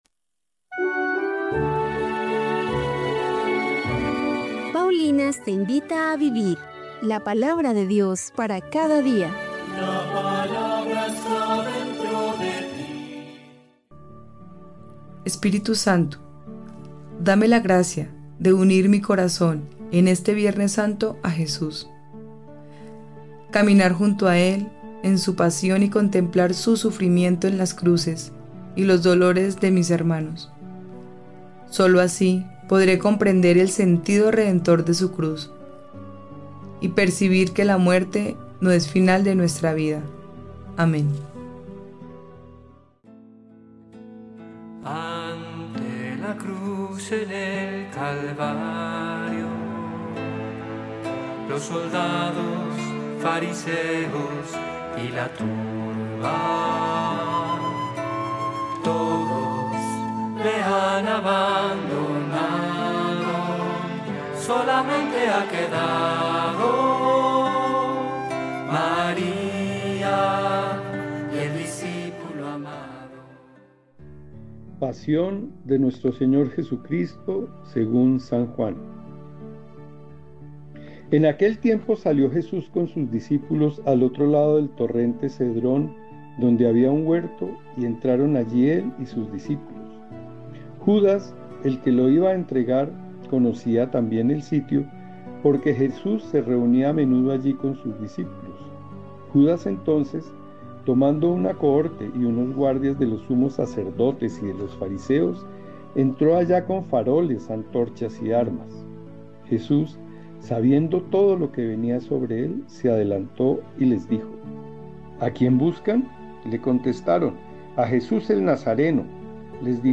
Lectura de los Hechos de los Apóstoles 8, 26-40